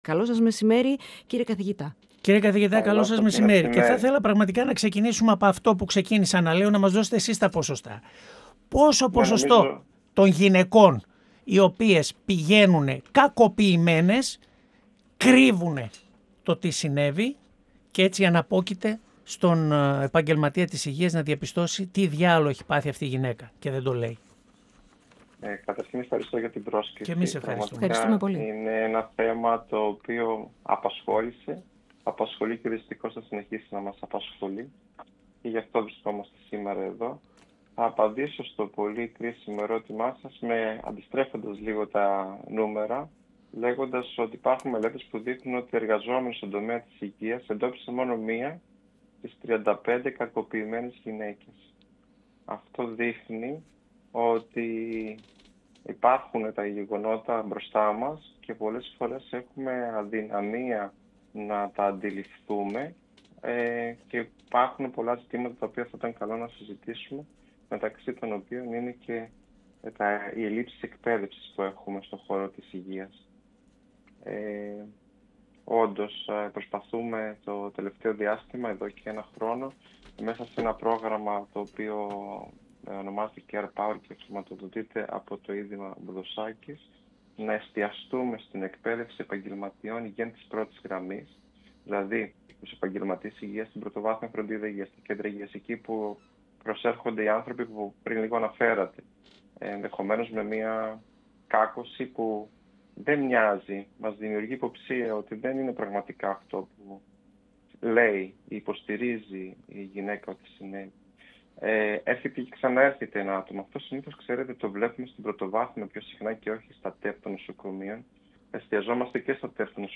προκειμένου να διαπιστώσουν ενδοοικογενειακή βία. 102FM Επομενη Σταση: Ενημερωση Συνεντεύξεις ΕΡΤ3